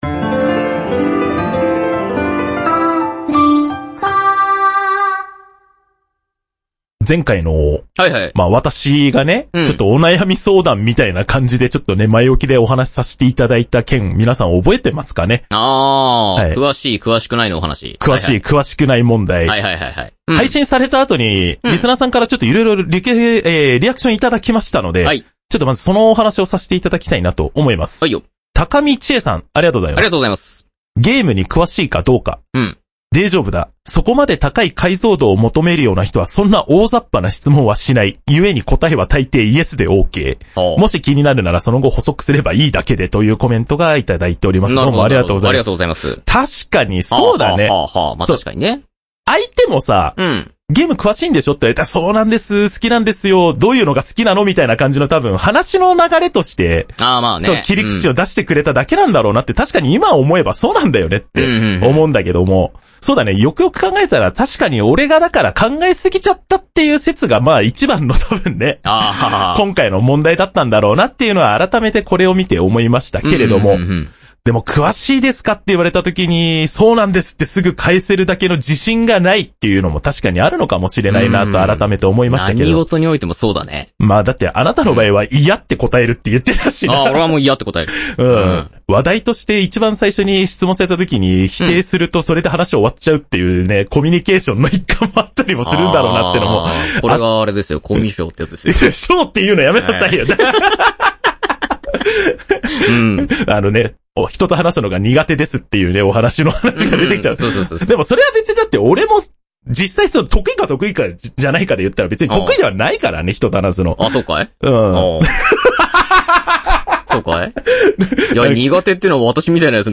ALFA制作番組「テレビゲームの中林」の公式ブログです。当時を懐かしみながら、他にも古今東西問わずリリースされたテレビゲームを、普及時・黎明期を共に触れてきた２人がレポートしながらゲームの面白さをお伝えします。